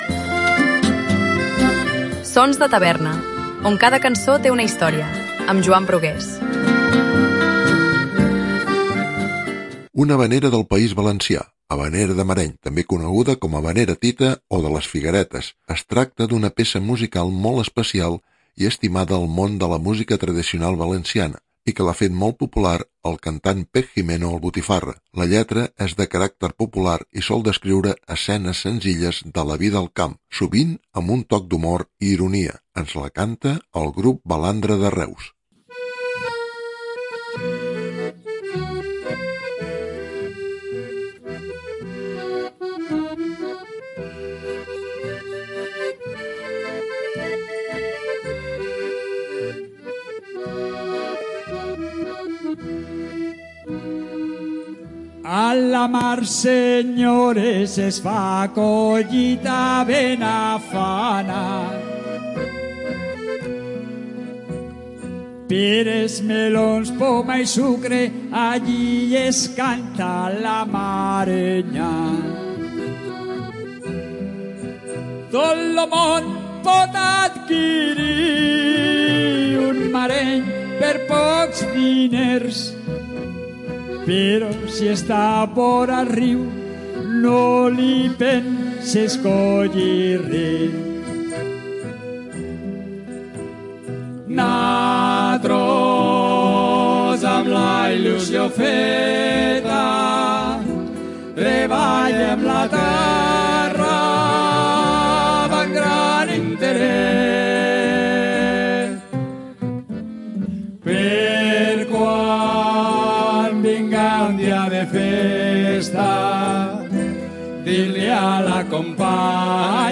Una havanera del País Valencià “Havanera del Mareny” també coneguda com tita o de les figueretes.
La lletra és de caràcter popular i sol descriure escenes senzilles de la vida al camp, sovint amb un toc d'humor i ironia. Ens la canta el grup Balandra de Reus.